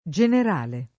Jener#le] agg. e s. m. — antiq. o lett. il tronc. come agg.: Ordinò general ministra e duce [ordin0 JJener#l min&Stra e dde] (Dante); contra il general costume de’ genovesi [k1ntra il Jener#l koSt2me de Jenov%Si] (Boccaccio) — lett. il tronc., come s. m., davanti a un cogn.: il general Cantore (più com. il generale Cantore) — sim. i cogn.